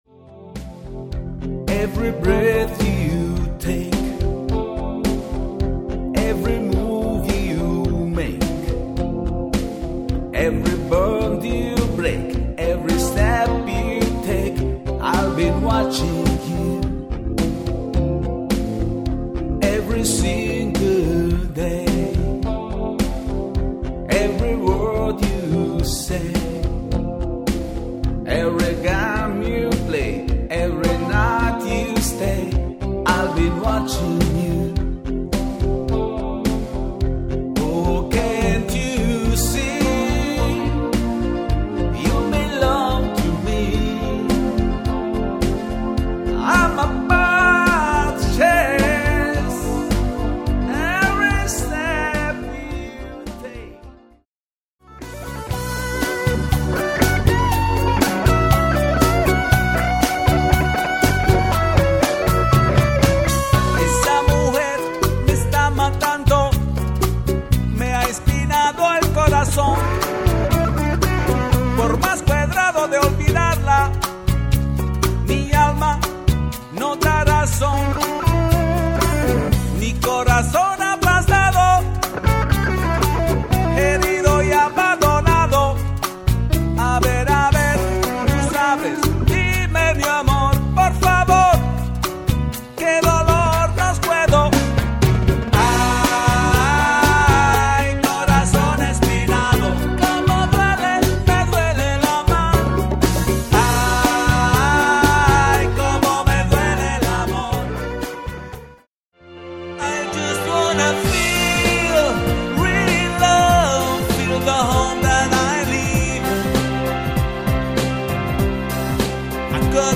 Immer wenn es um italienische Musik geht